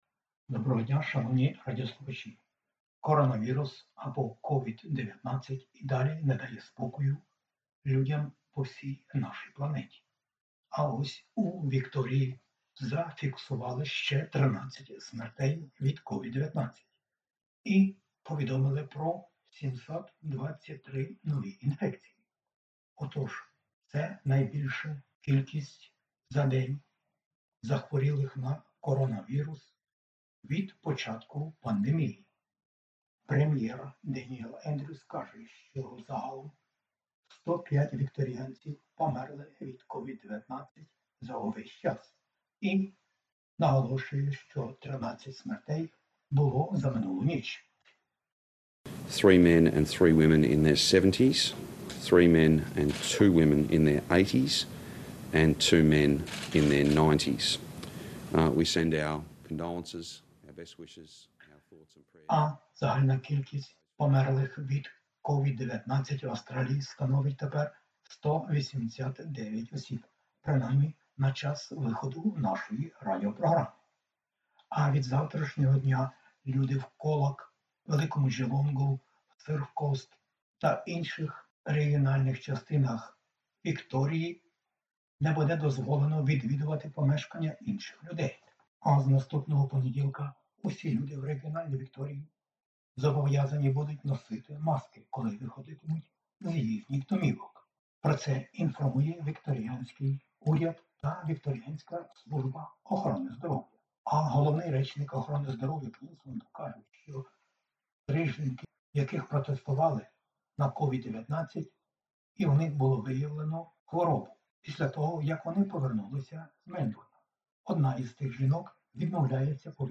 SBS НОВИНИ УКРАЇНСЬКОЮ МОВОЮ